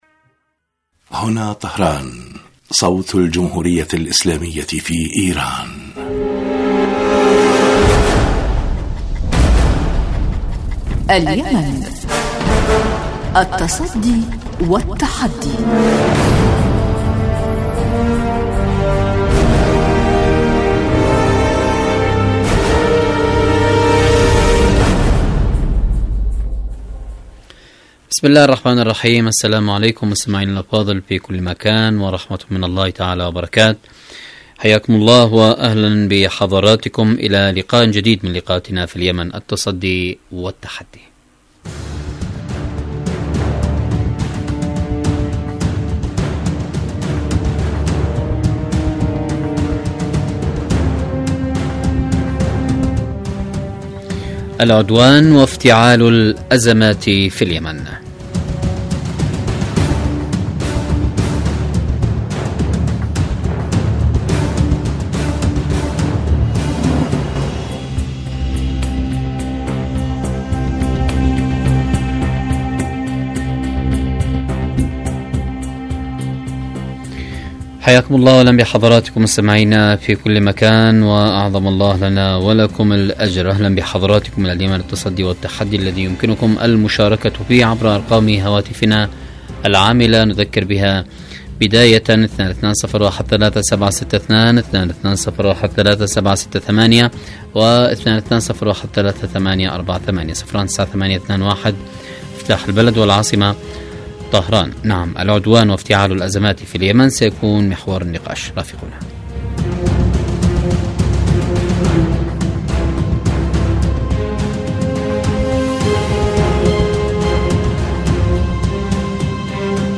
البرنامج يتناول بالدراسة و التحليل أخر مستجدات العدوان السعودي الامريكي على الشعب اليمني بحضور محللين و باحثين في الاستوديو.